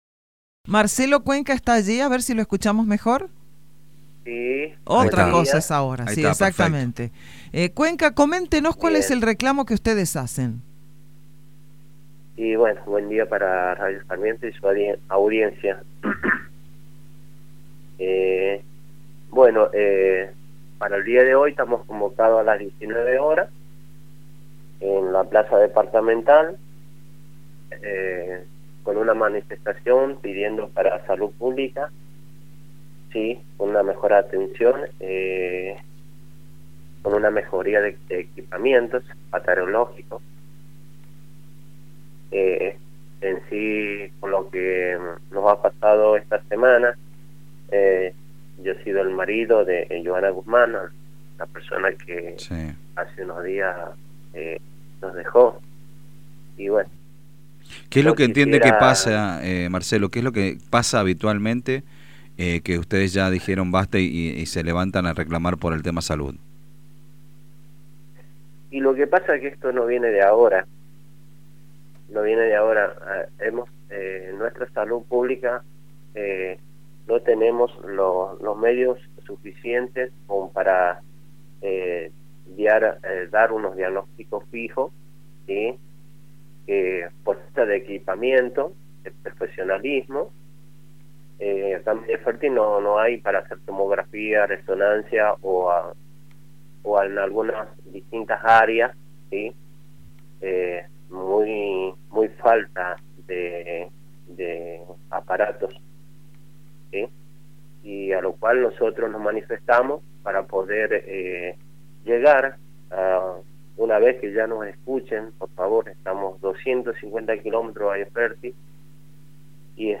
compartió sus preocupaciones durante una entrevista en LV5 Sarmiento